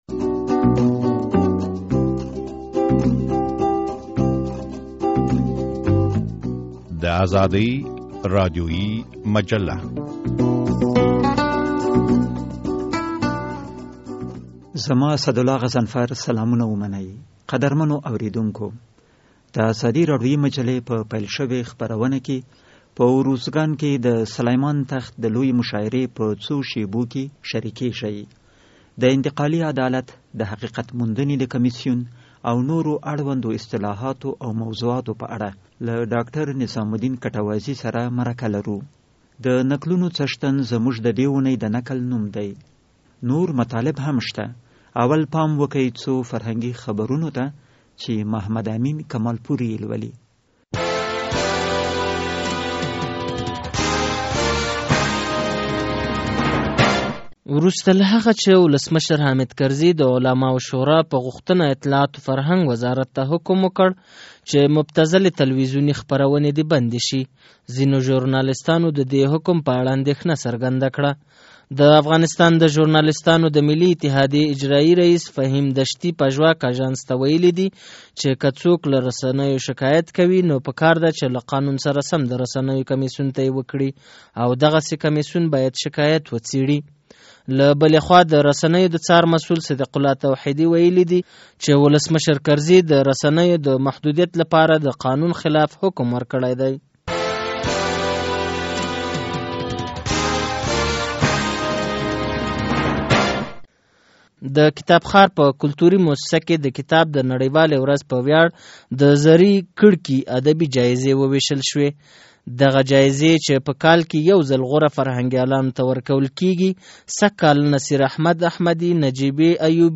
د ازادي راډیویي مجلې په دې خپروونه کې په اروزګان کې د سلیمان تخت د لویې مشاعرې په څو شېبو کې شرېکېږئ.